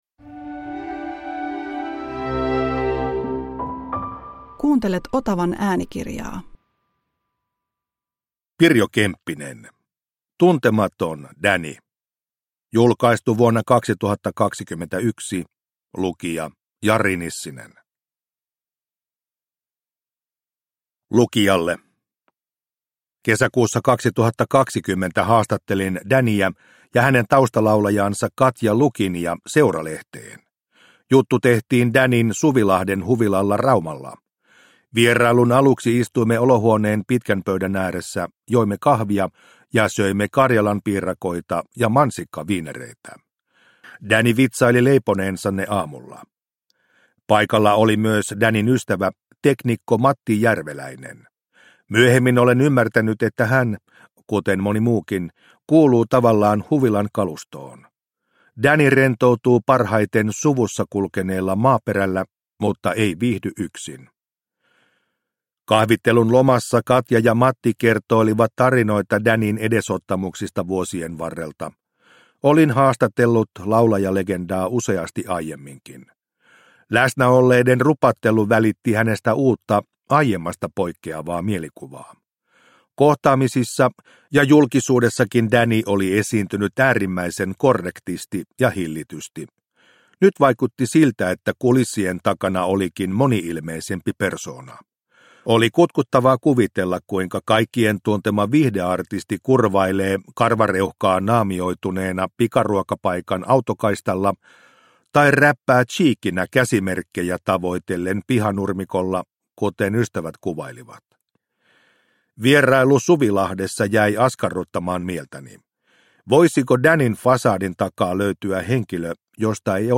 Tuntematon Danny – Ljudbok – Laddas ner